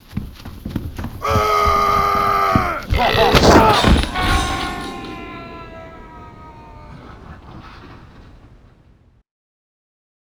throw.wav